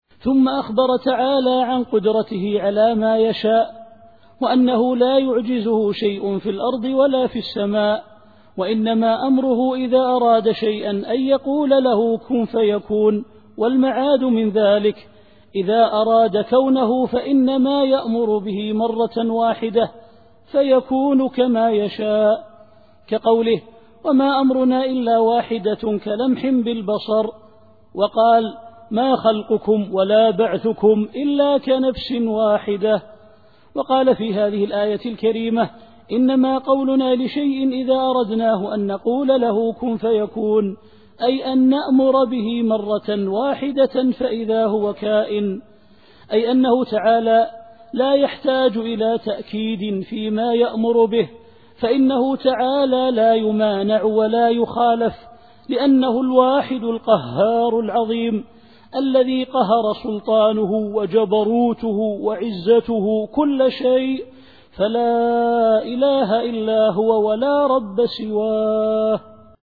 التفسير الصوتي [النحل / 40]